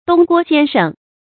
东郭先生 dōng guō xiān shēng 成语解释 泛指对坏人讲仁慈的糊涂人，比喻不分善恶，滥施仁慈的人 成语出处 明 马中锡《中山狼传》讲 东郭先生 救助中山狼，反而几乎被狼所害的故事。